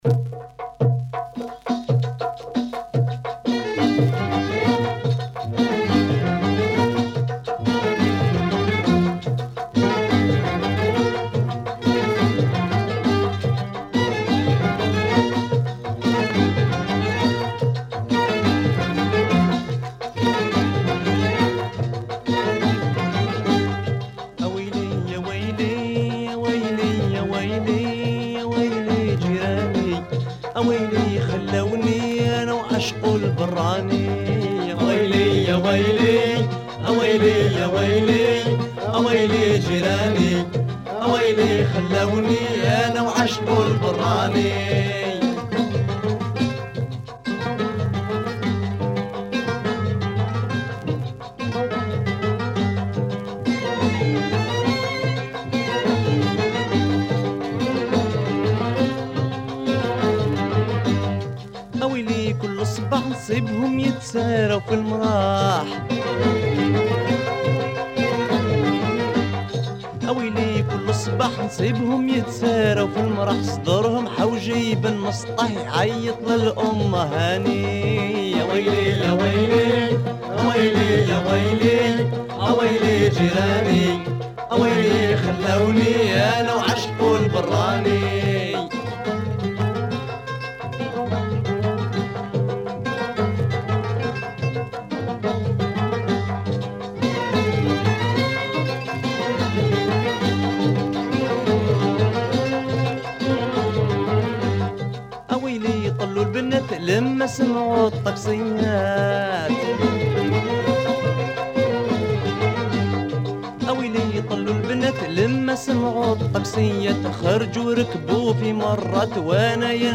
strings
Arabic & Persian